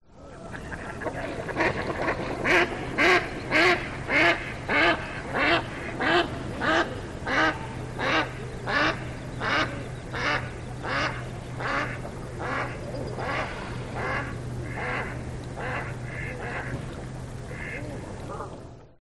Odgłosy zwierząt wiejskich
Kaczka
audio_hero_BirdMallardDuck_DIGIFX2-86.mp3